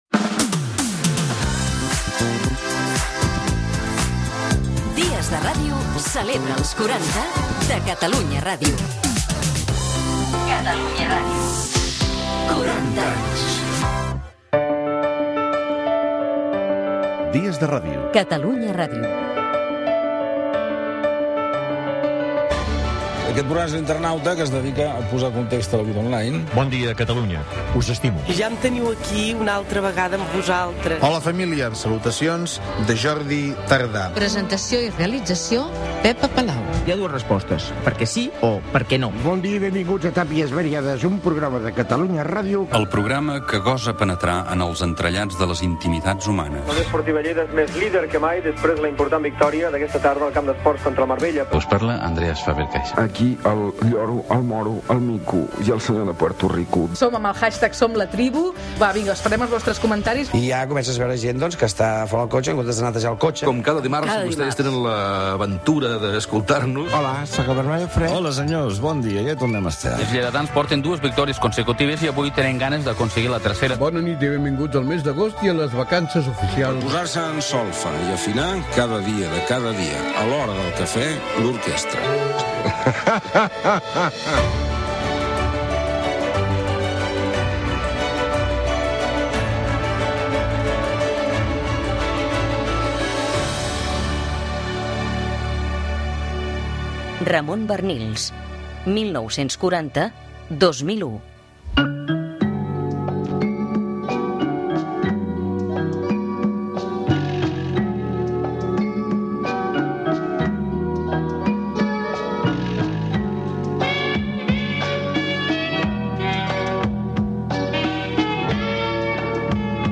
Indicatiu dels 40 anys de l'emissora, careta del programa, espai dedicat a Ramon Barnils i el programa "Bufet Lliure"
Divulgació